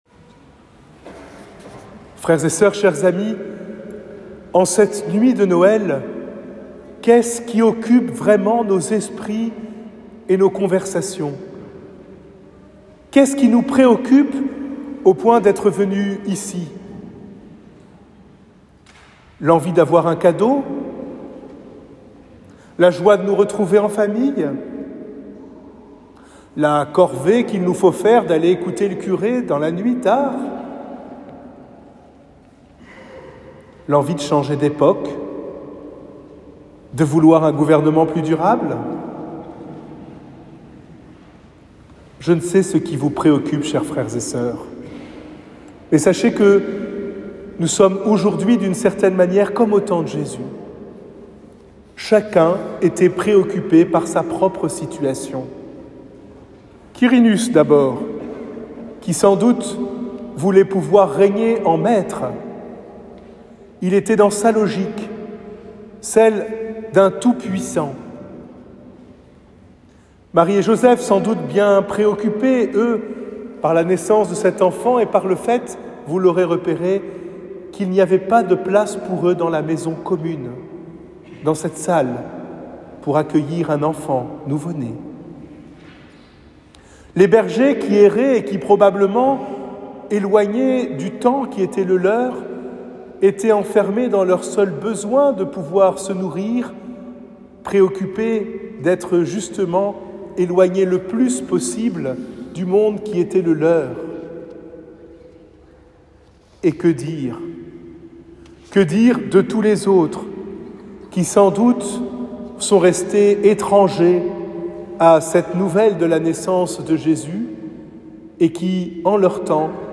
Homélie de la veillée de Noël 2024